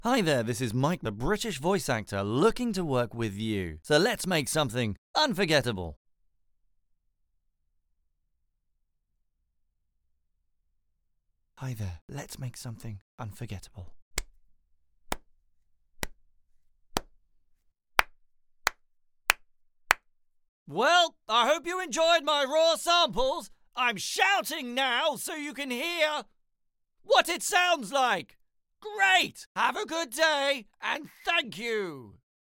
Male
Professional recording studio, Rode NT1A, Reaper, Izotope RX & more.
Studio Quality Sample
Normal, Silence, Claps & Shout